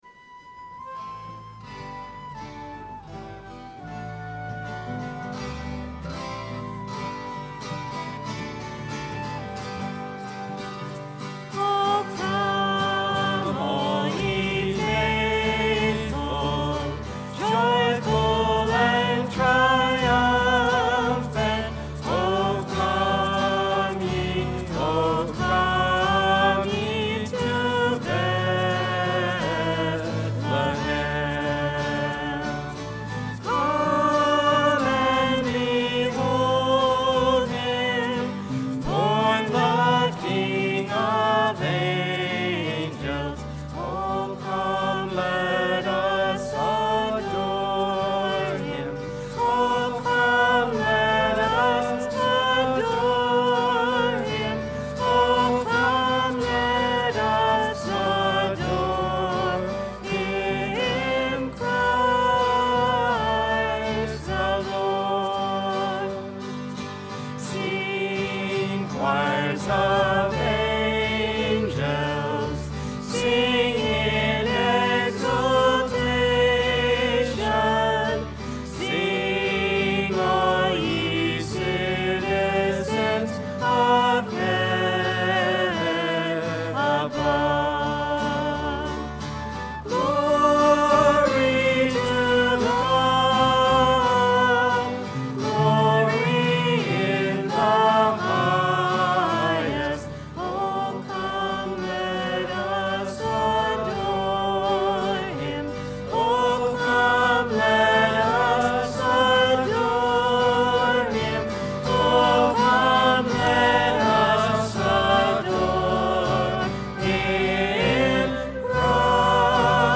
12/27/09 10:30 Mass Recording of Music - BK1030
Entrance: 01 O Come All Ye Faithful.mp3 - Note that the guitar mixer was not turned on.